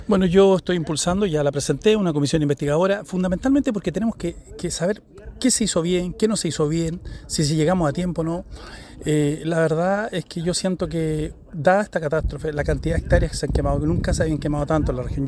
Previo a la publicación periodística, en conversación con Radio Bío Bío, Jouannet dijo que es necesario conocer si existen responsabilidades políticas en prevenir la emergencia, tomando en cuenta la cantidad de hectáreas consumidas por el fuego y el daño a familias y viviendas.
diputado.mp3